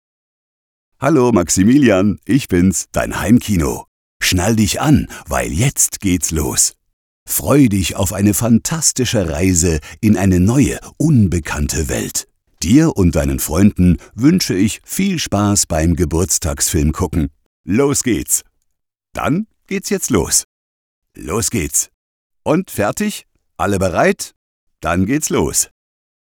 Sprecher / Announcer für Euer personalisiertes Heimkino Vorprogramm usw.